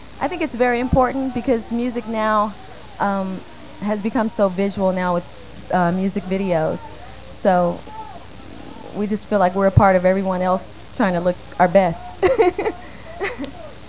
*KIDDING* Here are some audio clips of the girls talking about themsleves.